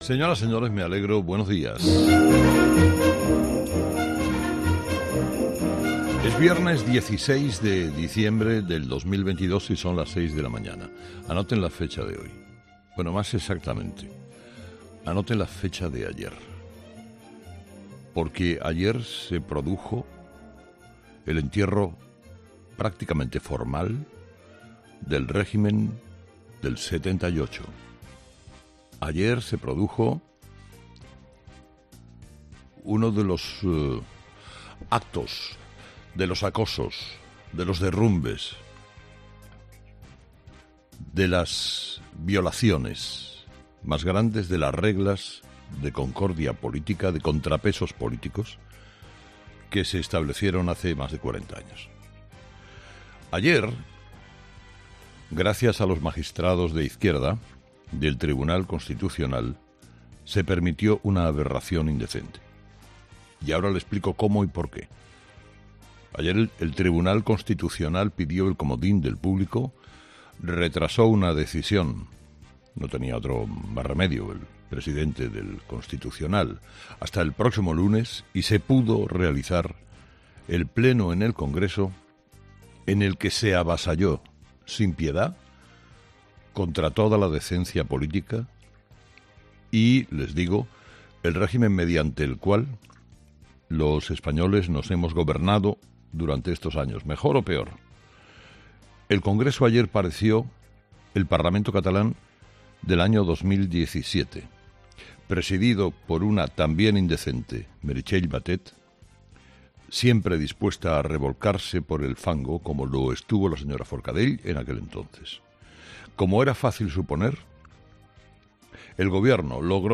Carlos Herrera repasa los principales titulares que marcarán la actualidad de este viernes 16 de diciembre en nuestro país